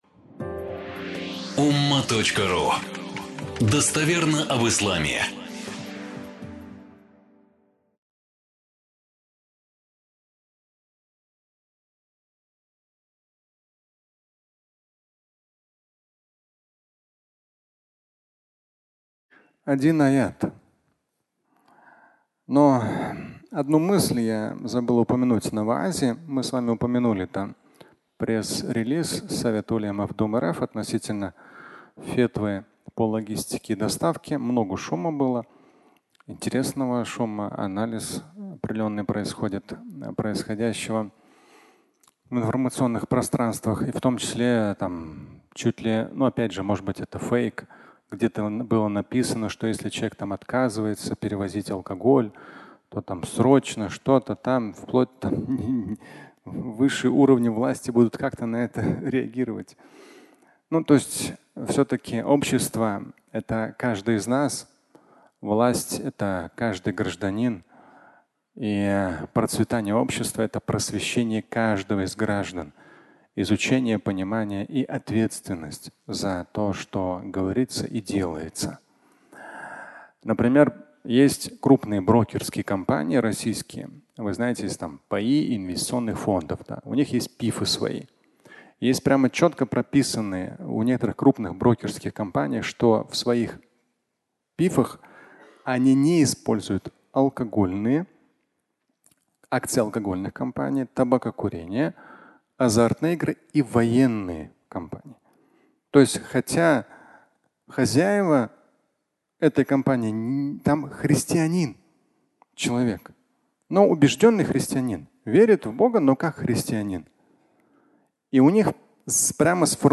Логистика и доставка 2 (аудиолекция)
Пятничная проповедь